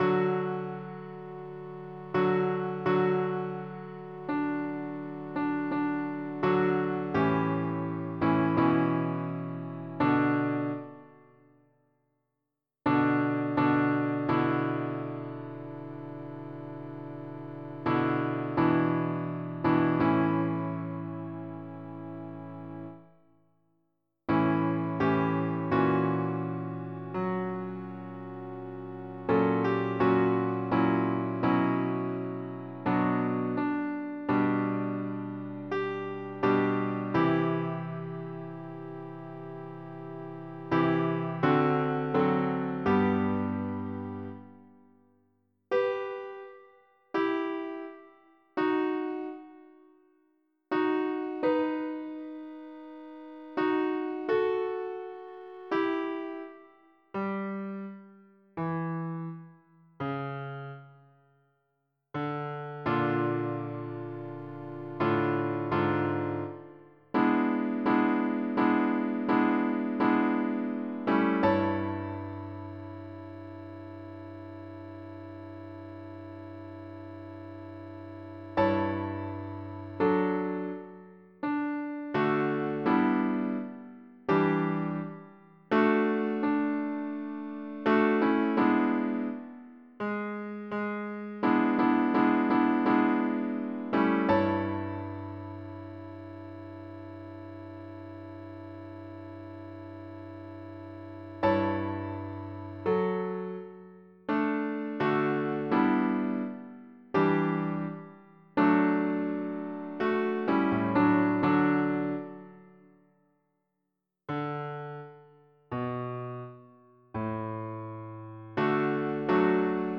MIDI Music File
Type General MIDI
calmsea.mp3